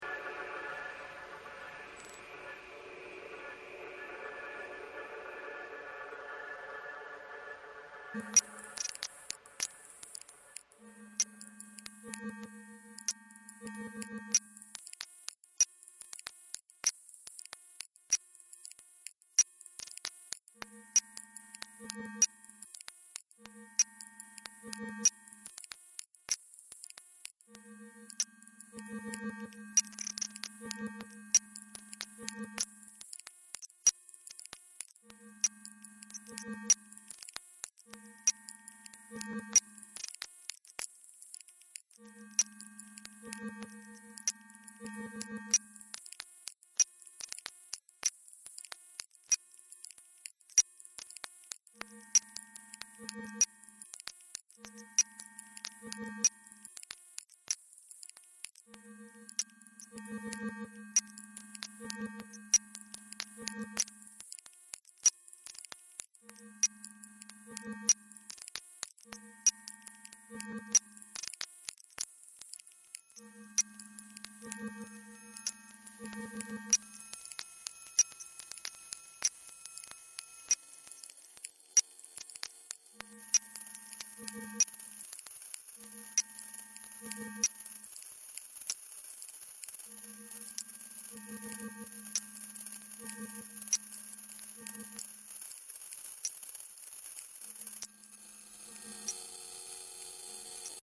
sound art
Soundscapes
Noise music